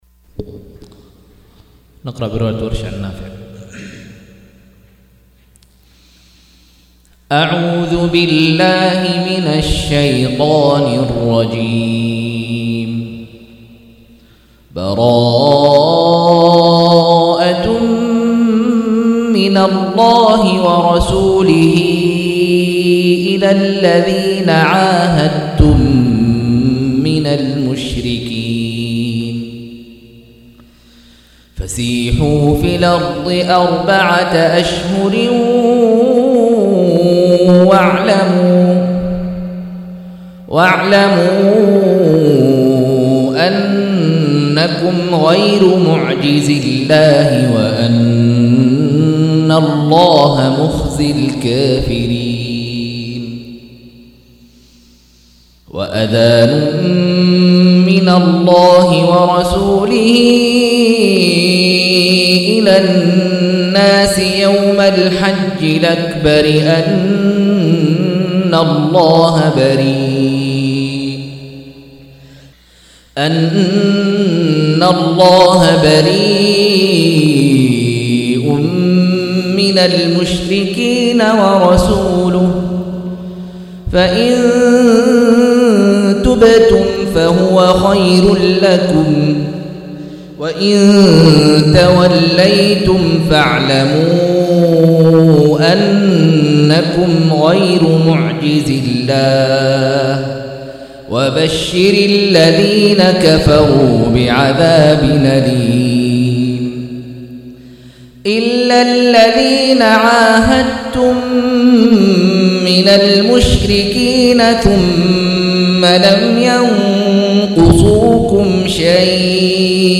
177- عمدة التفسير عن الحافظ ابن كثير رحمه الله للعلامة أحمد شاكر رحمه الله – قراءة وتعليق –